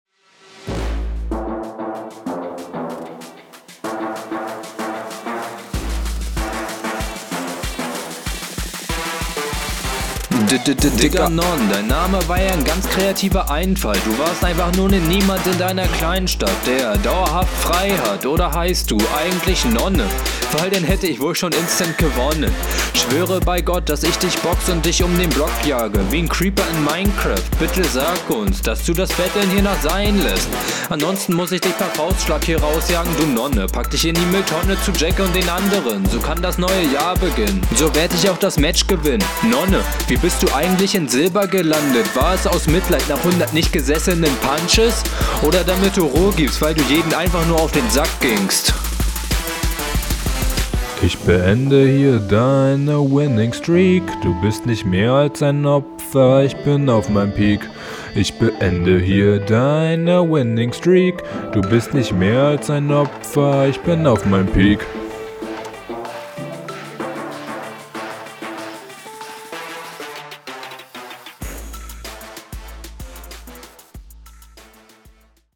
Cooler Beat. Obwohl er sehr simpel ist, bist du leider auch hier weit vom Takt …
Arbeite ein wenig an deiner Aussprache und deiner Lautstärke, finde deinen Gegner da stärker